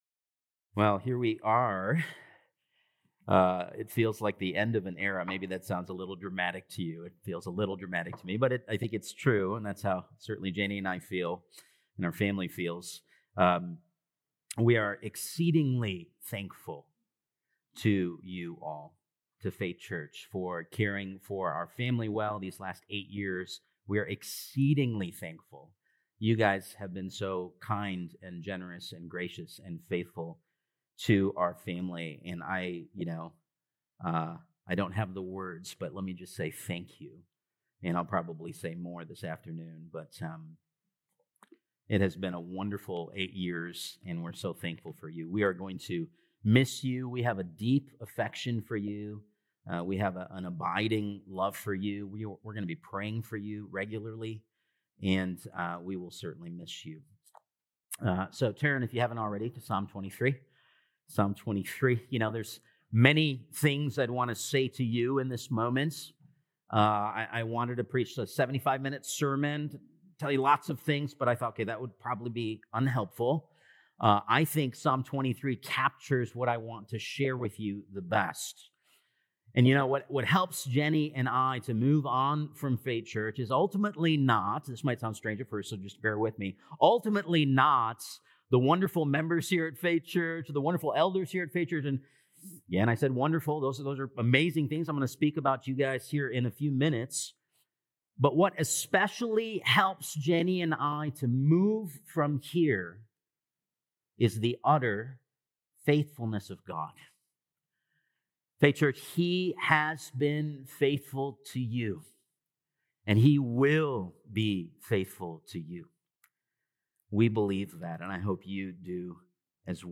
June 8th Sermon